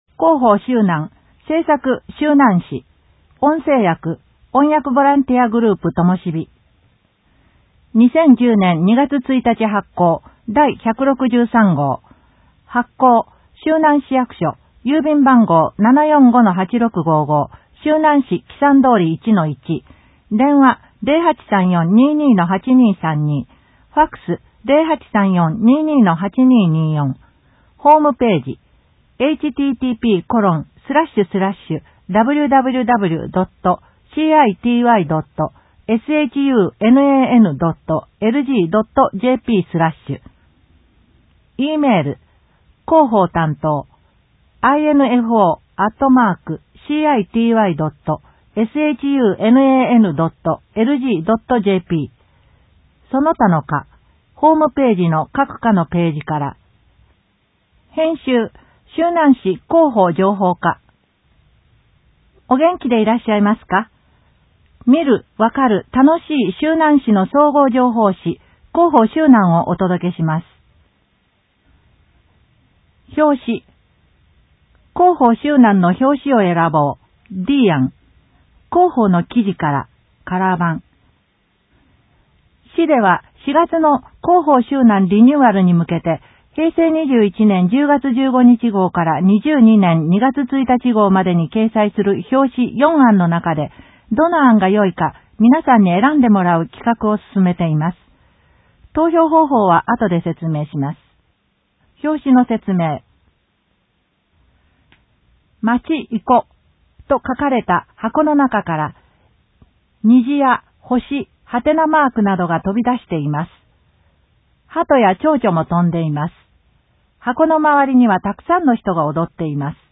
音訳広報
広報しゅうなんを、音読で収録し、mp3形式に変換して配信します。
この試みは、「音訳ボランティアグループともしび」が、視覚障害がある人のために録音している音読テープを、「点訳やまびこの会」の協力によりデジタル化しています。